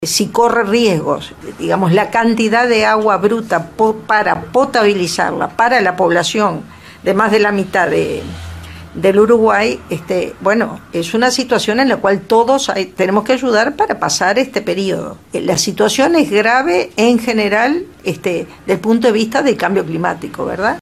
Este miércoles 3 de junio, luego de la reunión entre el Presidente Tabaré Vázquez y la Comisión para la protección del agua, De León habló en conferencia de prensa y exhortó a las industrias que trabajan en el área del río Santa Lucía a ingresar al sitio web del Ministerio, ya que con el sistema de visualización de datos, les permitirá saber si cumplen con la normativa